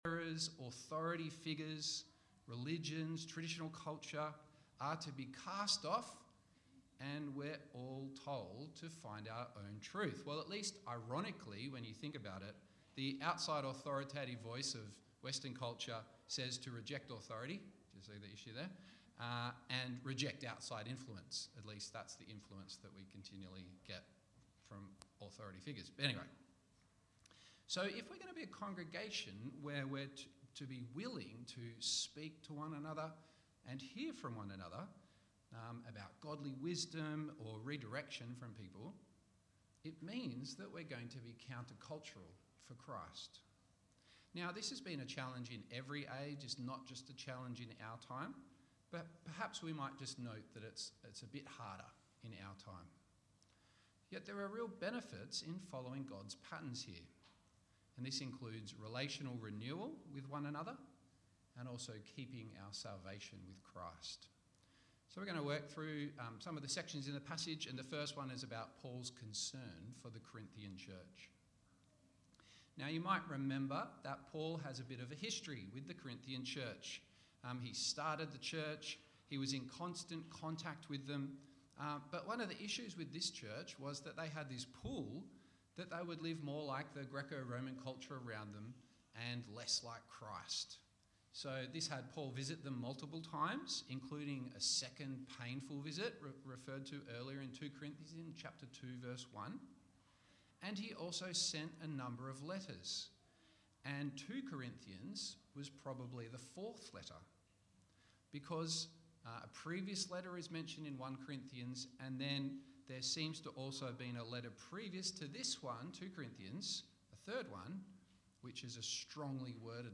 Passage: 2 Corinthians 7:2-16 Service Type: One off